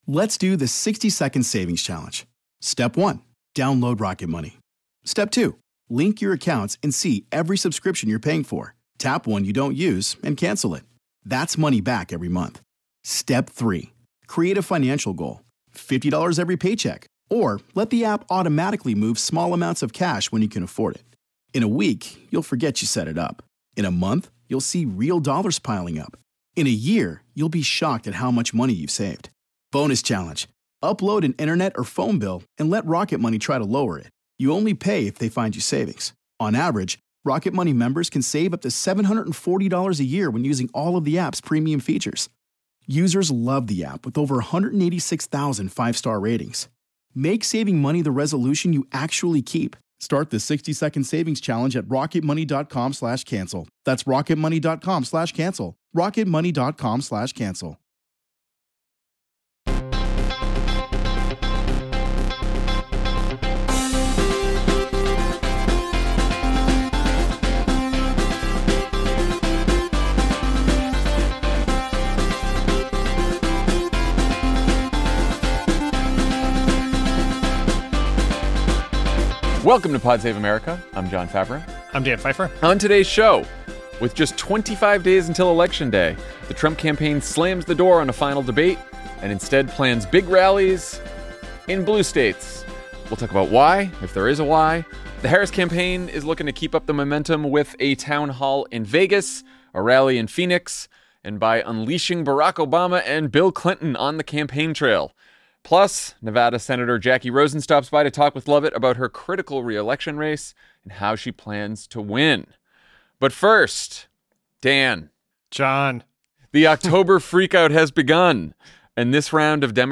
Jon and Dan break down the tightening polls, the rival campaigns' strategies for the final push, and the reasons why it's okay to worry—but not to panic. Then, Nevada Senator Jacky Rosen joins Lovett to talk about her tight race for reelection and how she plans to pull off a win.